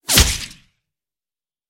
Вы найдете разные варианты ударов – резкие, глухие, с эхом – для использования в монтаже, играх или других творческих задачах.
Звук пули пролетающей сквозь голову